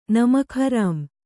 ♪ namak harām